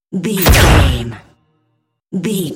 Dramatic hit laser shot
Sound Effects
heavy
intense
dark
aggressive